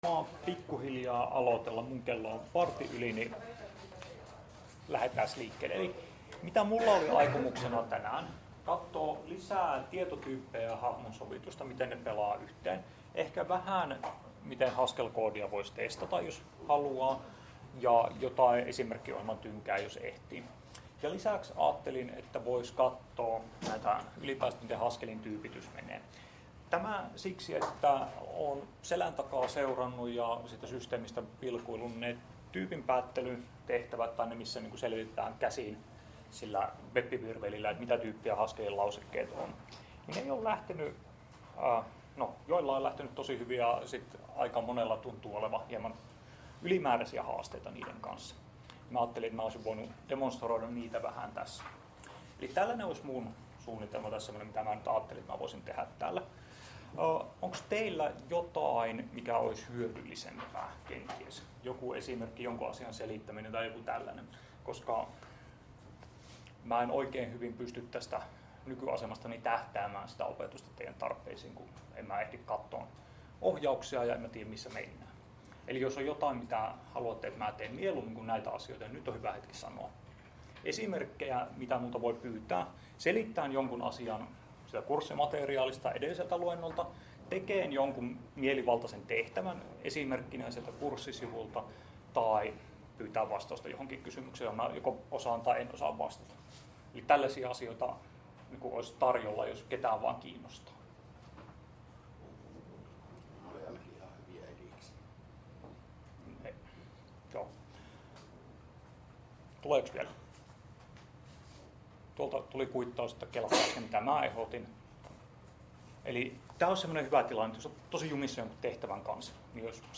Luento 07.10.2019 — Moniviestin